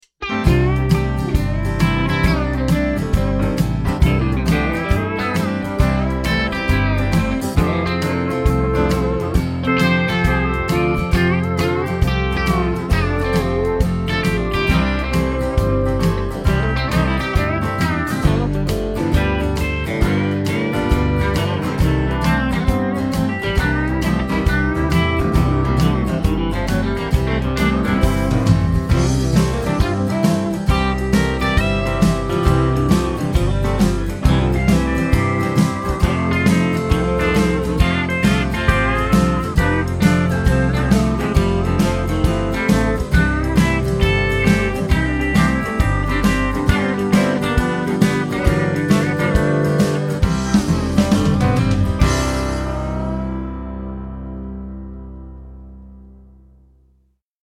Genre: Country.